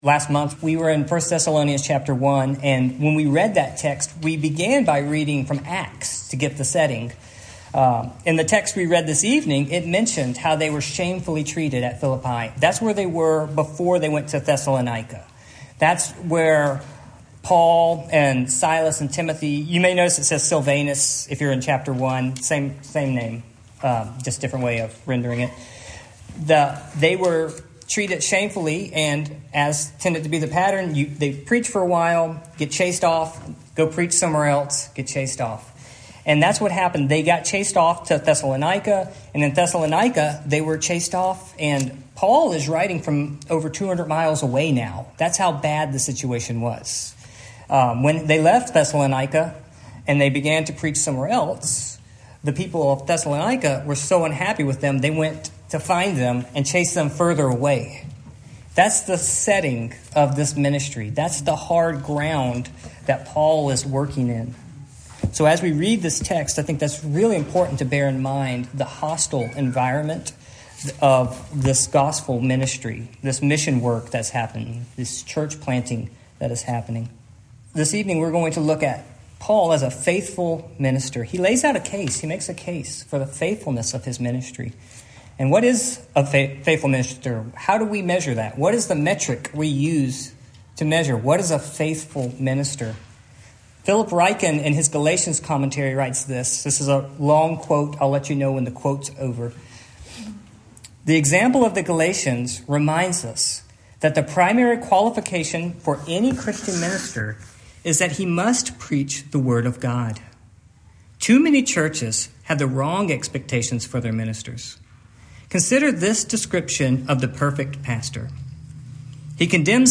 1 Thessalonians Passage: 1 Thessalonians 2:1-16 Service Type: Evening